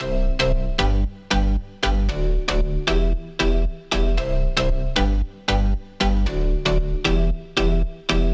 空灵的马林巴合成器1
描述：F大调的马林巴合成器，空气清新。延迟的，大气的